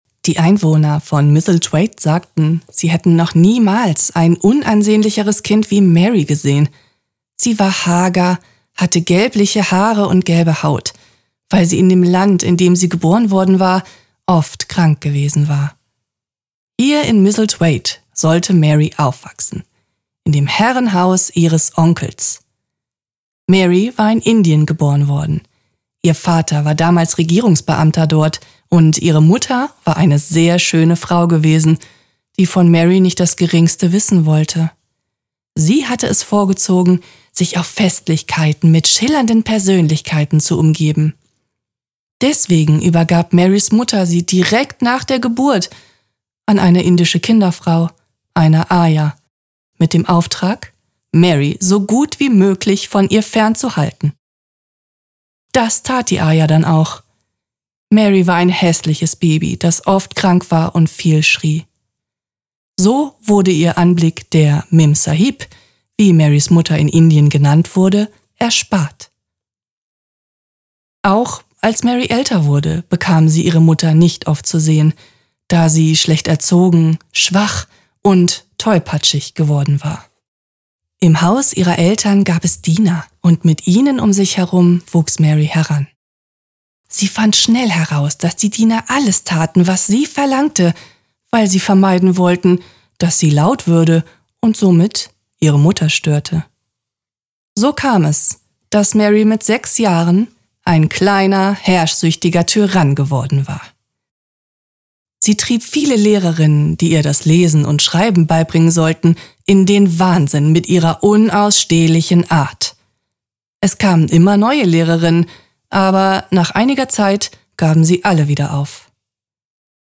Eine zeitlose Geschichte voller Geheimnisse und Entdeckungen. Die Erzählung verwebt Natur, Freundschaft und Hoffnung. Der Tonfall ist ruhig, atmosphärisch und märchenhaft getragen.
Hörbuch
Mit Gefühl, Tiefe und Atmosphäre.